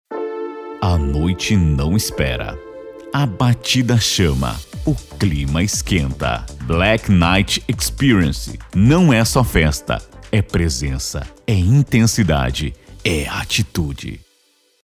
Impacto: